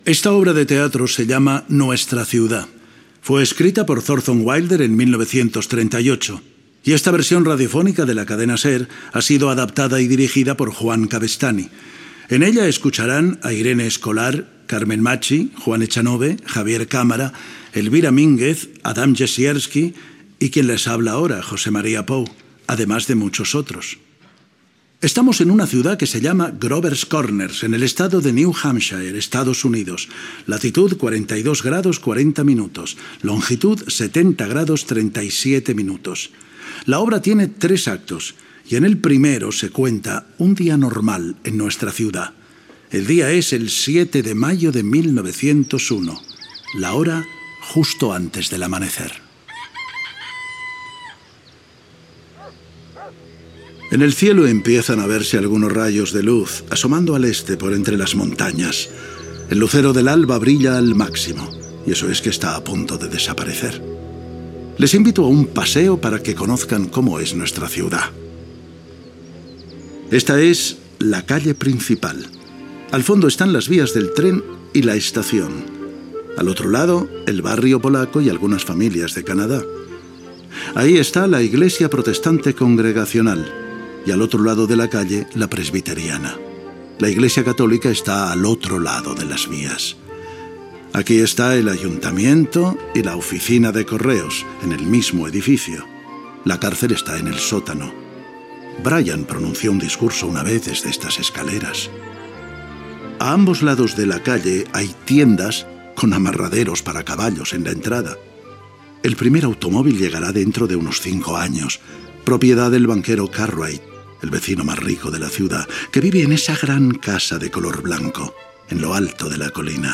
Inici de l'adaptació radiofònica de l'obra ‘Our Town’, escrita per Thorton Wilder el 1938. El narrador fa la presentació de l'obra i situa l'acció.
Ficció